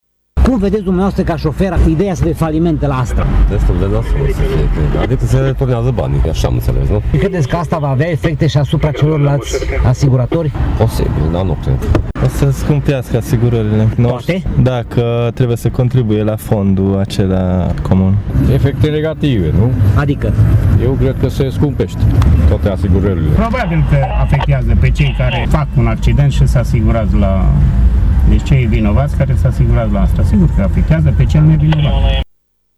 Niciunul dintre şoferii cu care am stat de vorbă nu crede în redresarea ASTRA asigurări, fiind convinşi că preţurile poliţelor de asigurări vor creşte pe viitor.